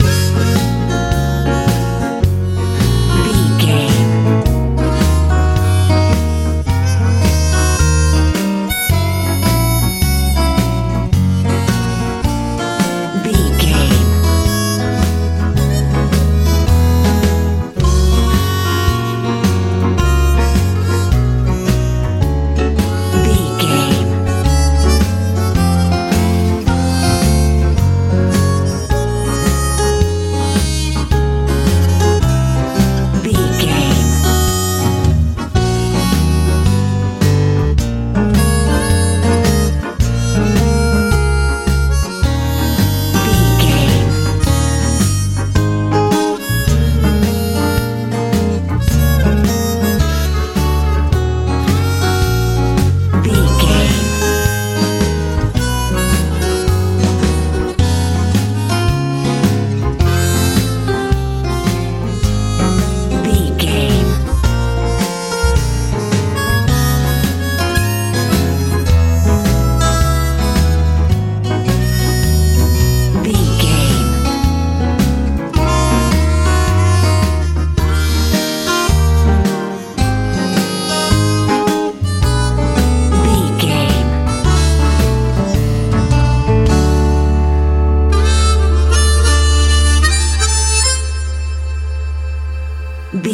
country ballad
Ionian/Major
F♯
mellow
piano
acoustic guitar
drums
bass guitar
bouncy
dreamy
poignant